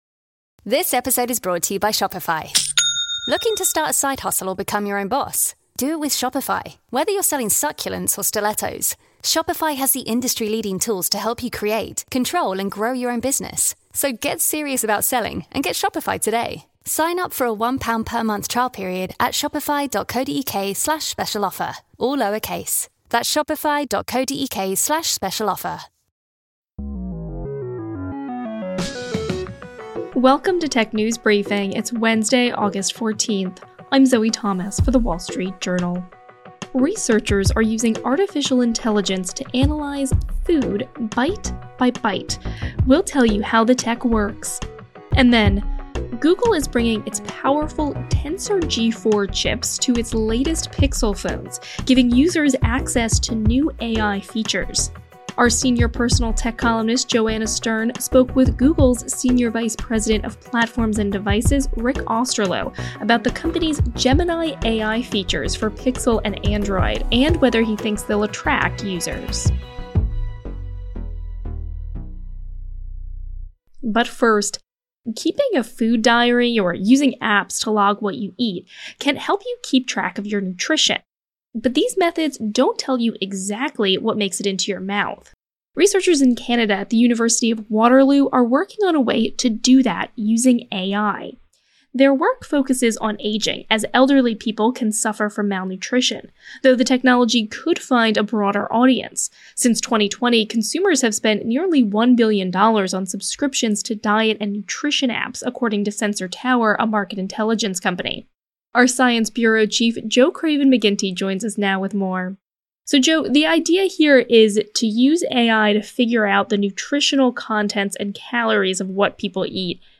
Google’s Senior Vice President of Platforms and Devices Rick Osterloh, spoke with WSJ senior personal tech columnist Joanna Stern about the new features. Plus, researchers are developing tech can that measure food consumption by the bite.